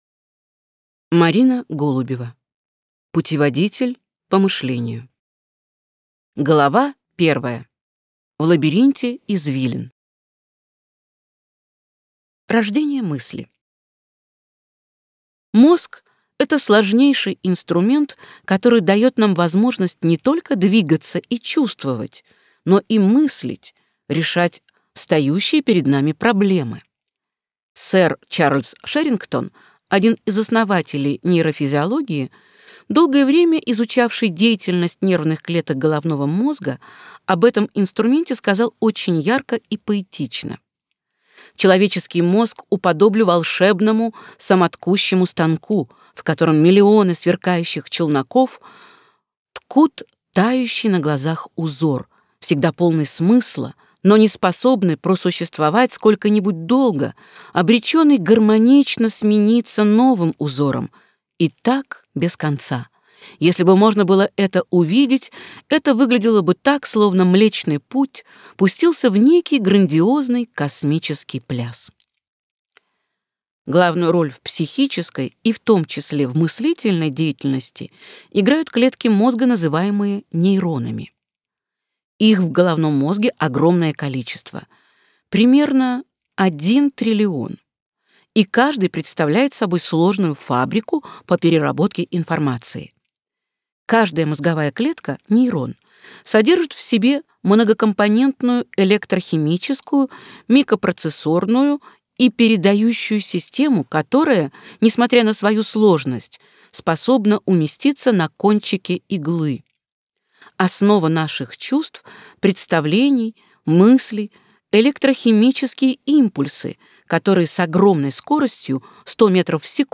Аудиокнига Путеводитель по мышлению | Библиотека аудиокниг
Прослушать и бесплатно скачать фрагмент аудиокниги